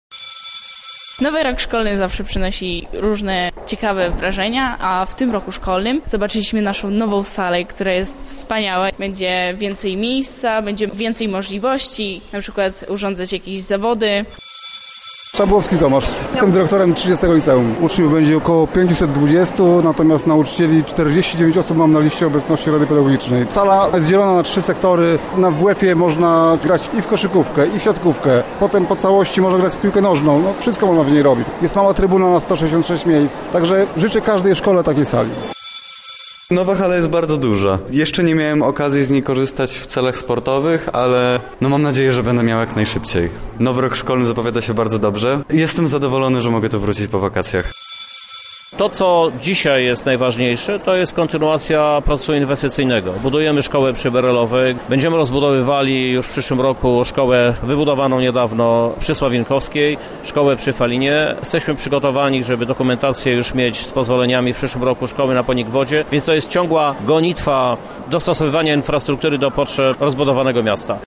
Za nami miejska inauguracja roku szkolnego.
Prezydent Lublina – Krzysztof Żuk niezmiennie twierdzi, że to właśnie oświata jest w centrum wszystkich decyzji, które są w mieście podejmowane: